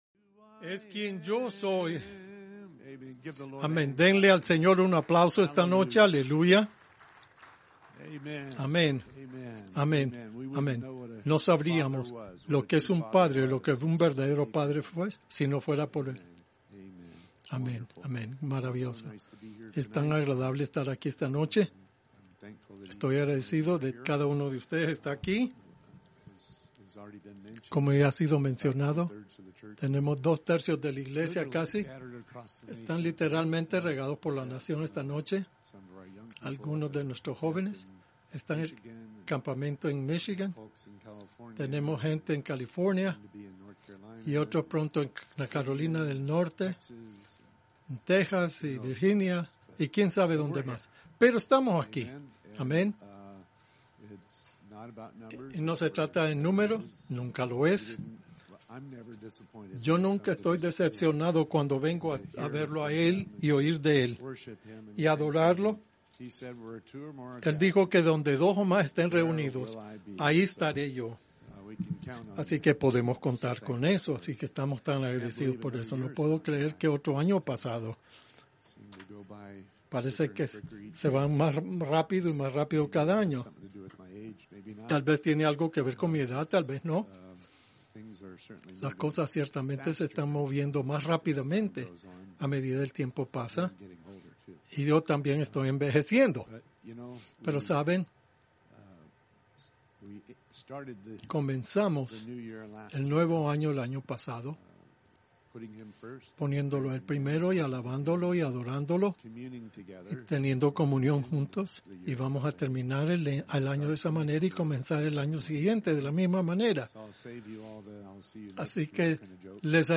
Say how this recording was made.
Servicios de Vigilia